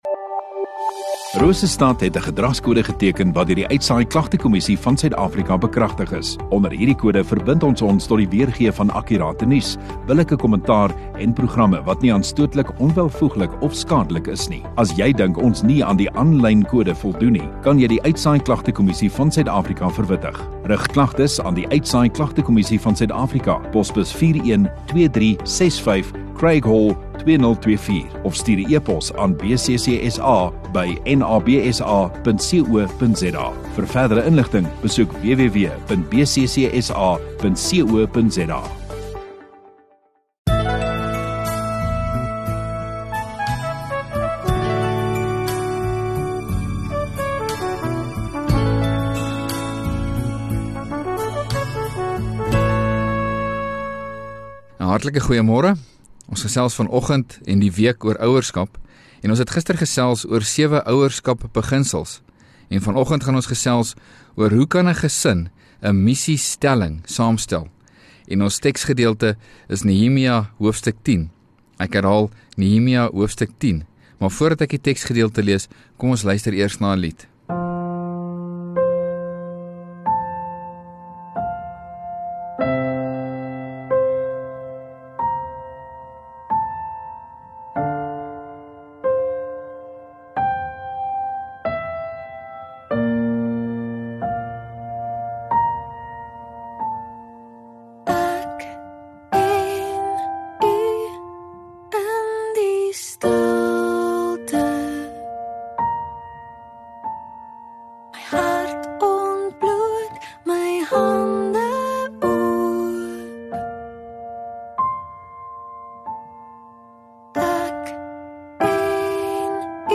2 Feb Sondagoggend Erediens